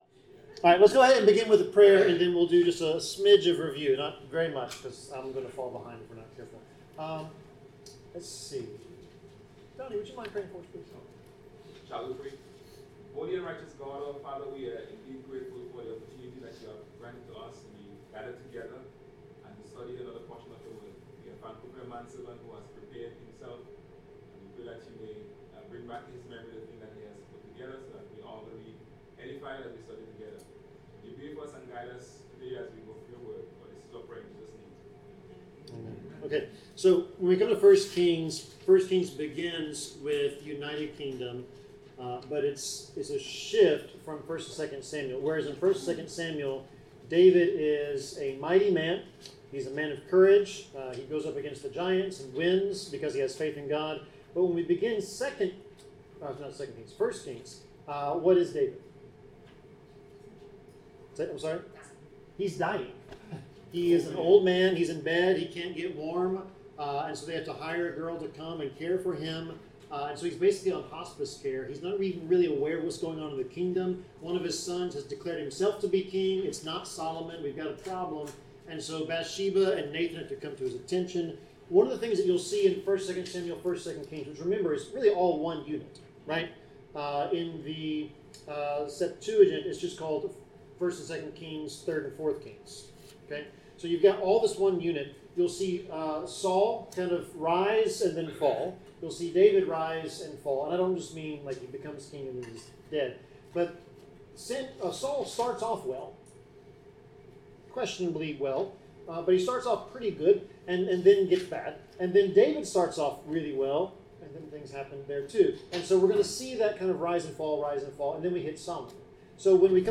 Bible class: 1 Kings 18-19 (From Mount Carmel to Mount Horeb)
Service Type: Bible Class